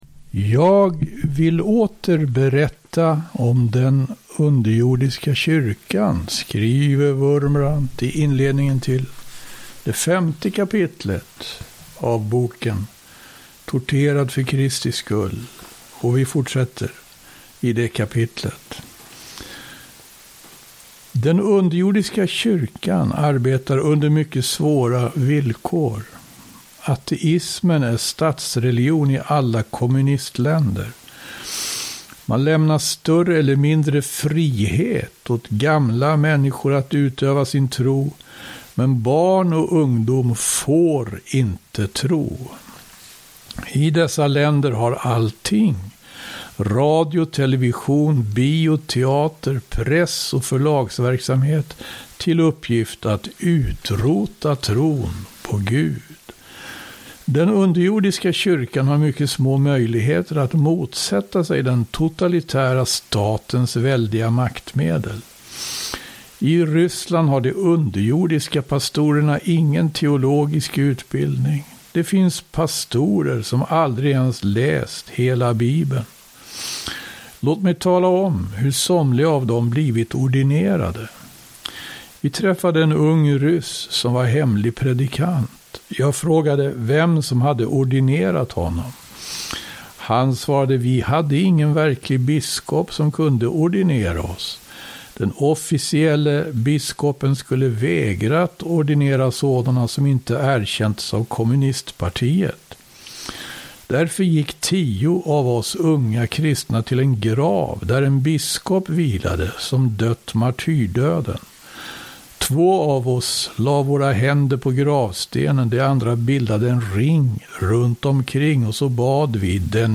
läser